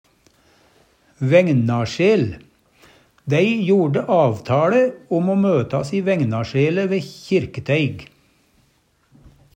vægnasjel vegskil; der vegen deler seg Eintal ubunde Eintal bunde Fleirtal ubunde Fleirtal bunde vægnasjel vægnasjele vægnasjel vægnasjelo Eksempel på bruk Dei jorde avtaLe om o møtas i vægnasjele ve Kirketeig. Høyr på uttala Ordklasse: Substantiv inkjekjønn Kategori: Karakteristikk Attende til søk